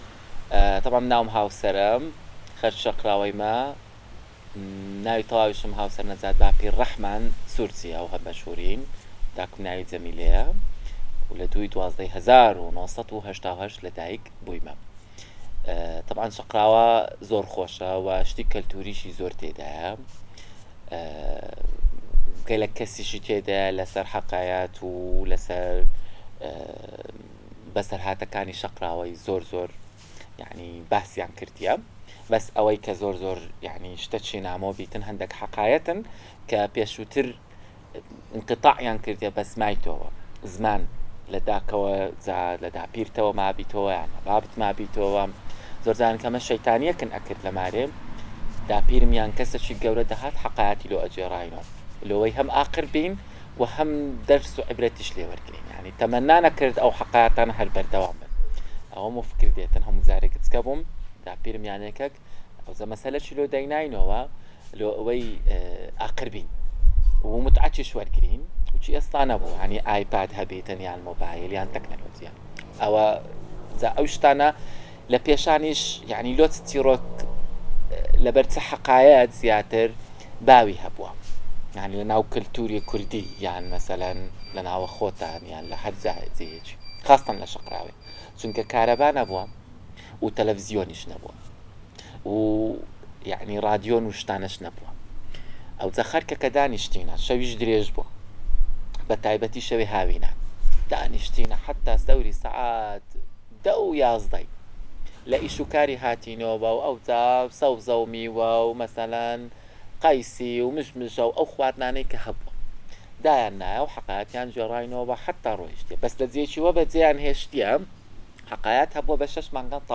The Kurdish and Gorani Dialect Database